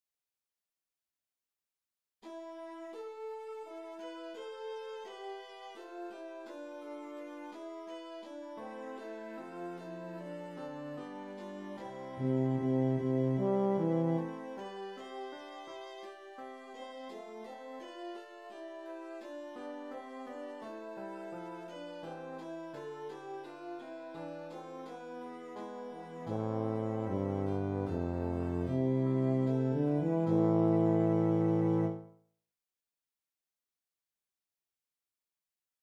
Chorproben MIDI-Files 481 midi files